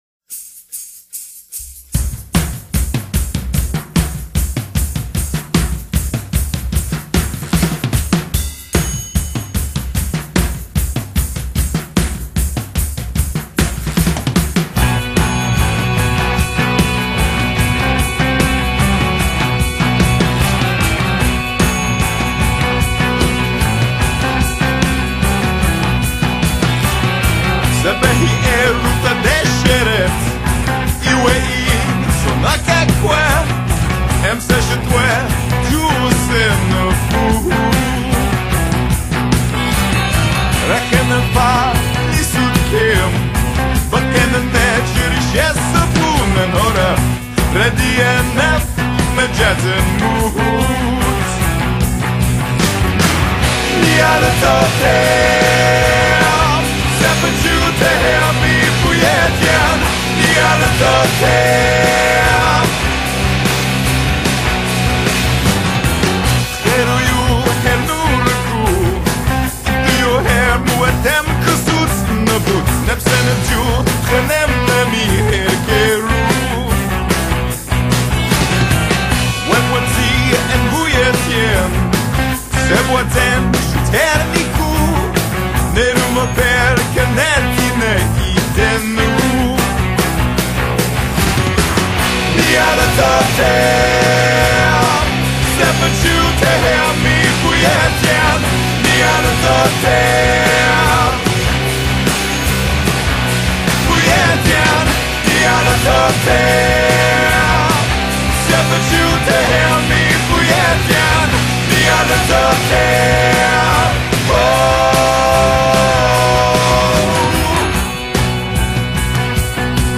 Der Ström beginnt um 18 Uhr, das Hörspiel um 18:15, die zweite Folge um 19:30.